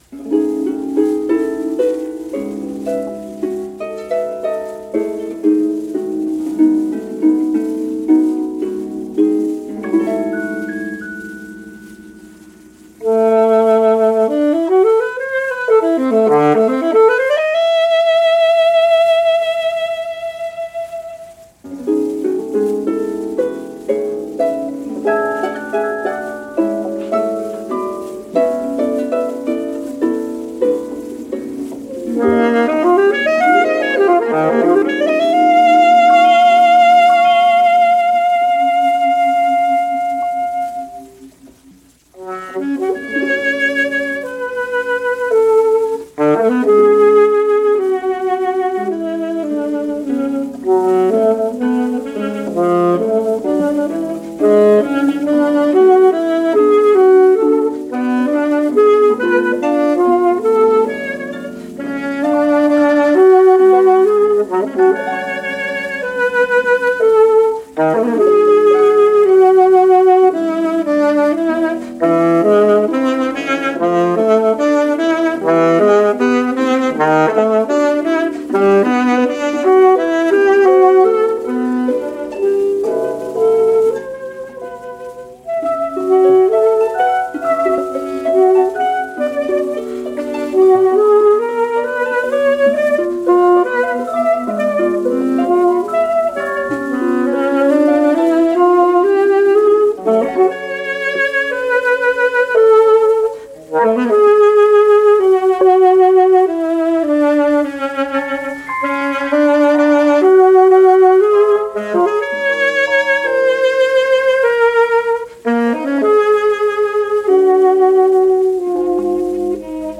ORTF Radio, Paris
Over to Paris this week for a radio broadcast recital
saxophone
piano
Harp
percussion